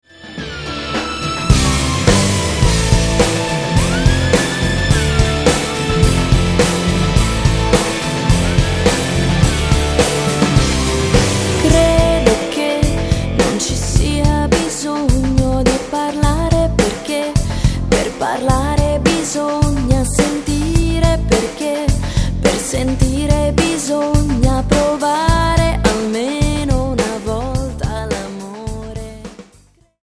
Voce
Tastiere
Basso
Chitarre
Batteria